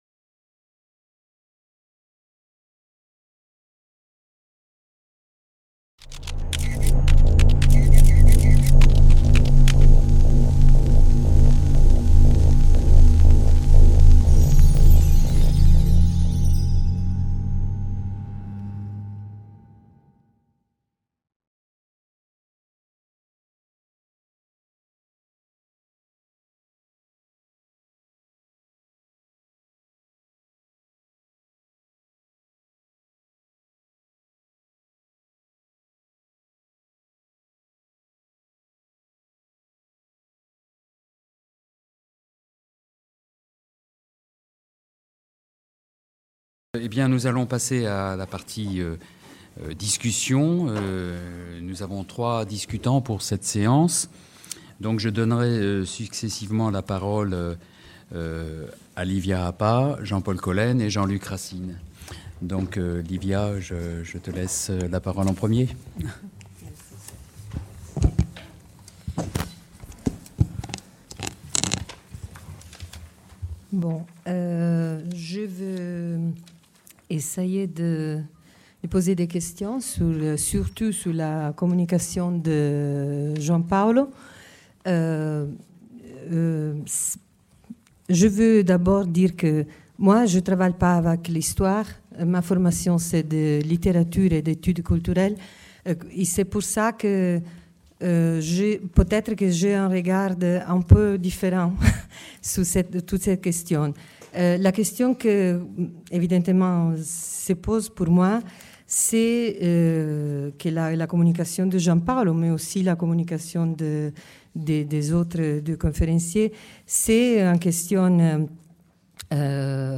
3/B Passions et récits des décolonisations/Discutants et conclusion/Il était une fois. Les indépendances africaines… La fin des empires ? | Canal U
Il était une fois. Les indépendances africaines… La fin des empires ? Rencontre internationale